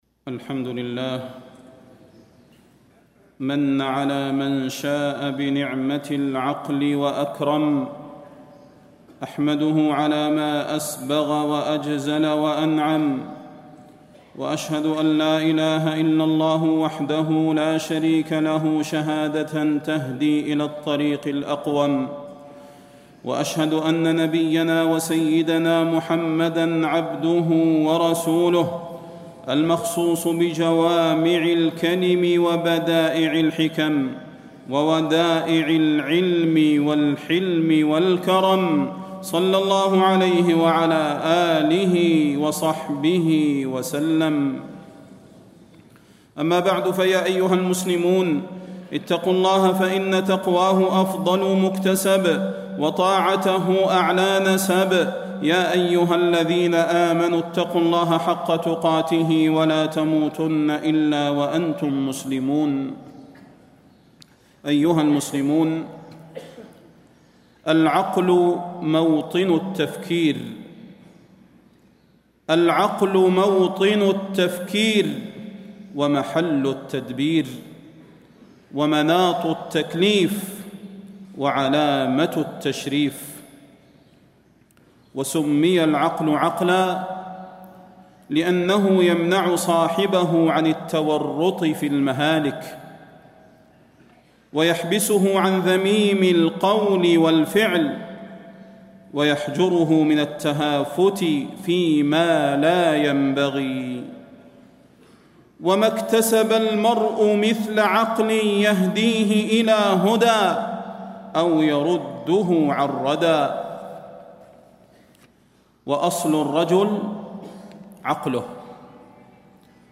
تاريخ النشر ٣٠ ربيع الأول ١٤٣٥ هـ المكان: المسجد النبوي الشيخ: فضيلة الشيخ د. صلاح بن محمد البدير فضيلة الشيخ د. صلاح بن محمد البدير صفات العقلاء والحمقى